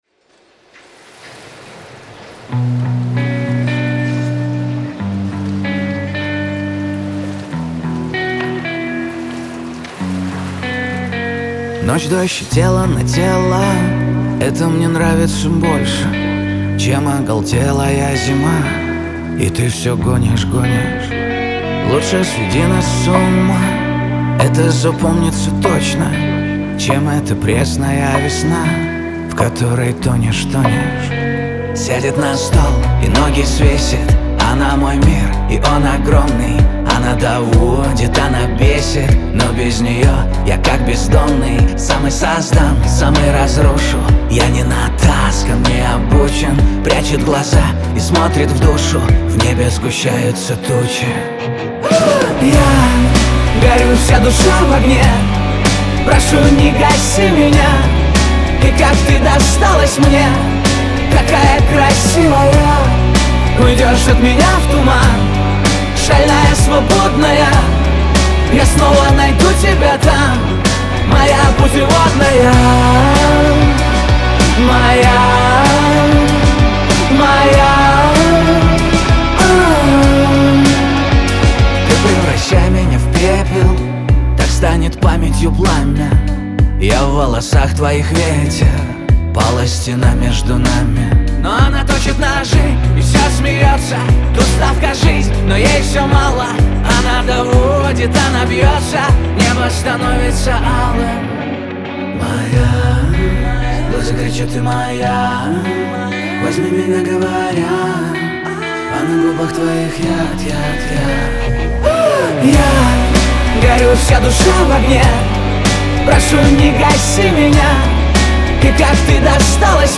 ХАУС-РЭП
pop , эстрада